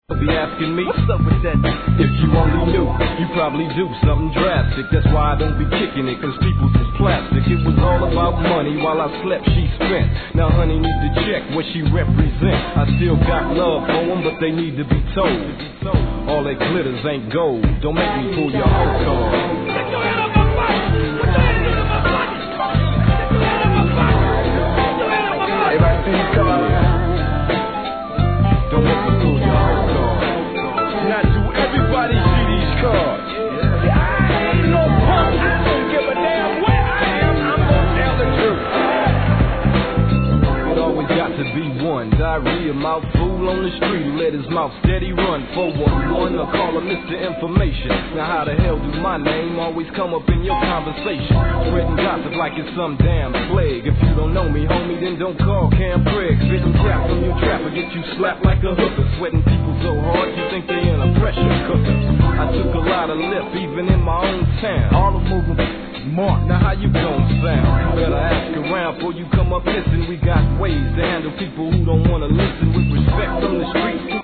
G-RAP/WEST COAST/SOUTH
JAZZYなピアノに女性のコーラス・フックが洒オツ1995年、メロ〜G!!